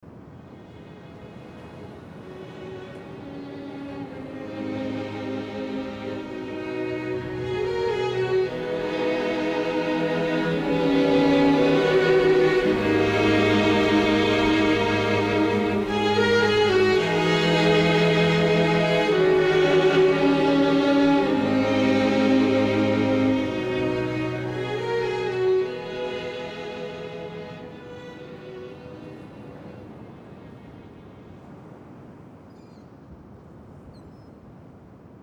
Genre : Funk, R&B, Soul